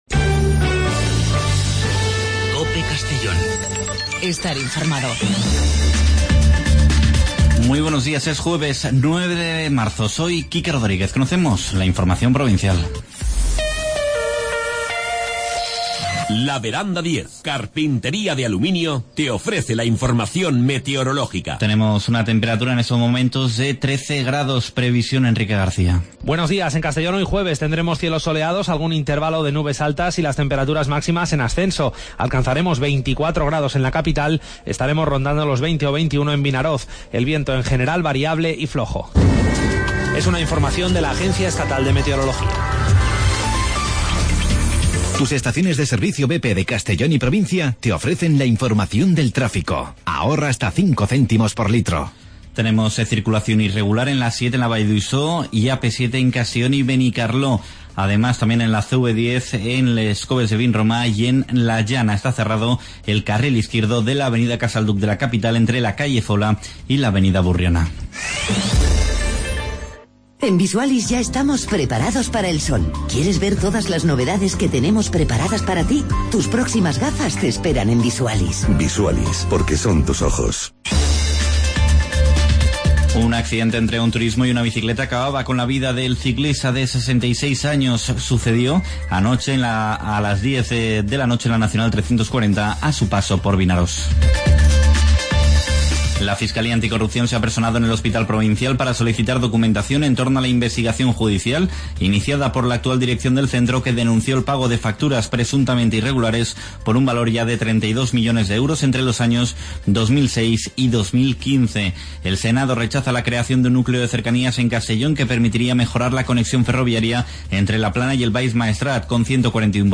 Informativo Matinal (9 de marzo)